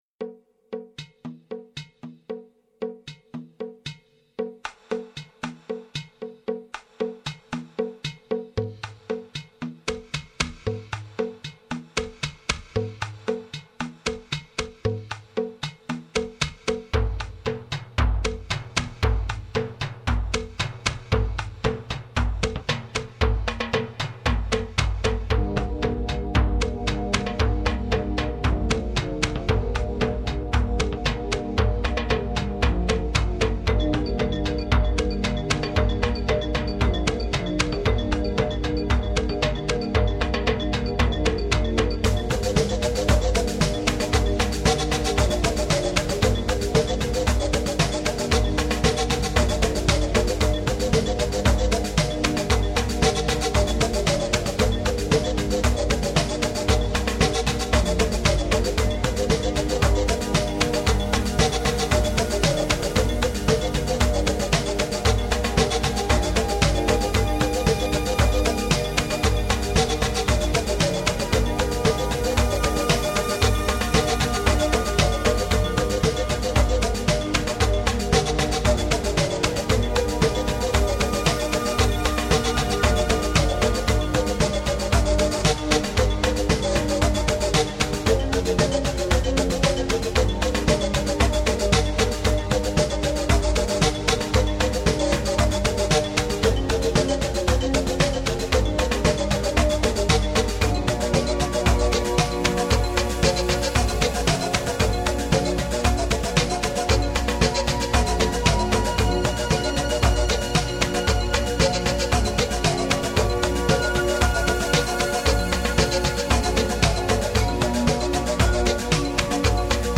CD11  LATIN FLAVOURED CHILLOUT TUNES（源自拉丁风格音乐）